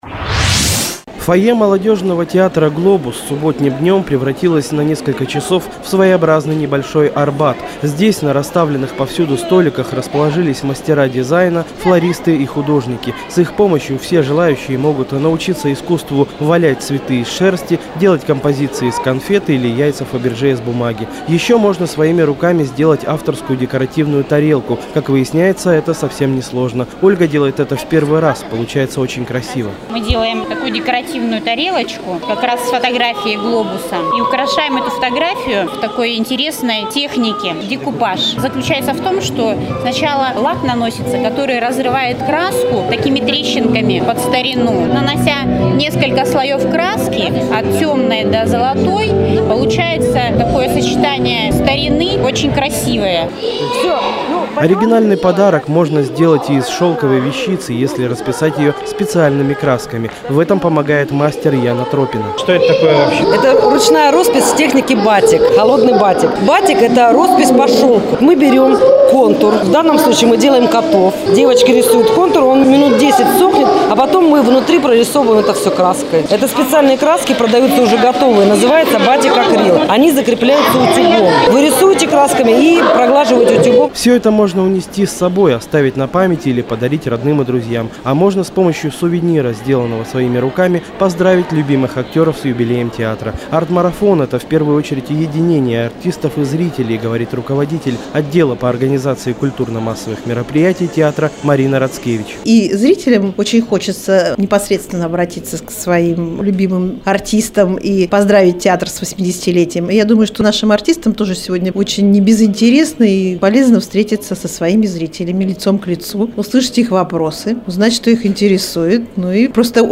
Арт-марафон "Территория творчества" в "Глобусе", 3 апреля 2010
Мои репортажи, вышедшие в эфир Радио "Городская волна"